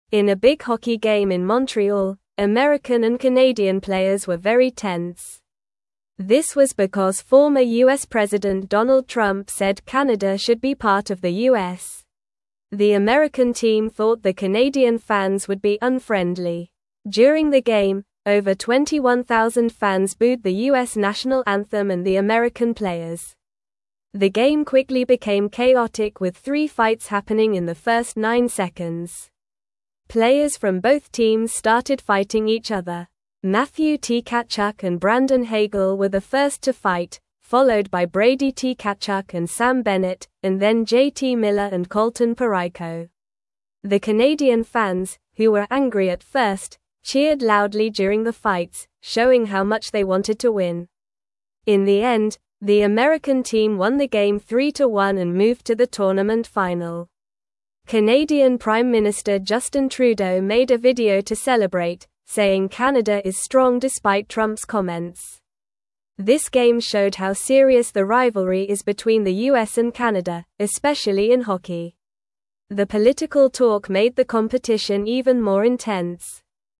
English-Newsroom-Lower-Intermediate-NORMAL-Reading-Hockey-Game-with-Fights-and-Excited-Fans.mp3